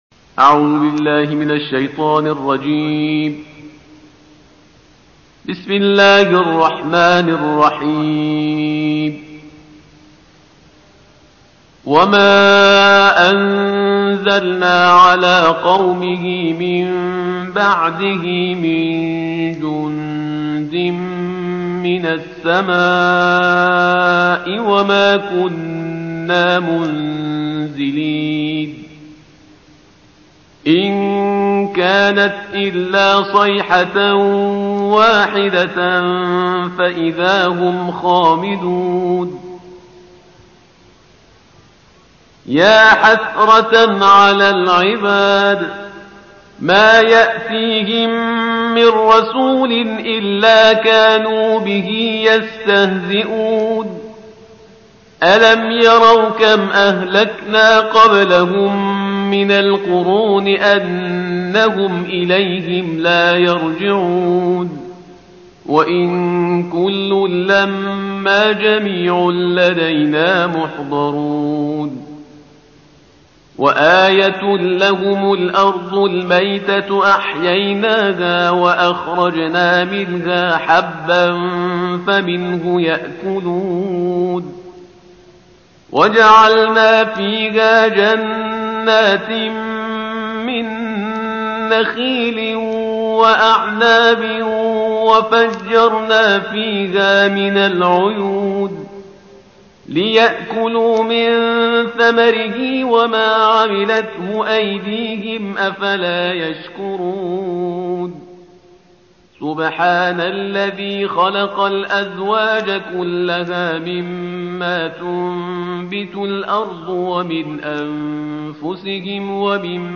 صوت جزء بیست و سوم قرآن کریم با صدای دلنشین استاد شهریار پرهیزکار